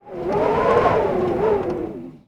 dmobs_wind.ogg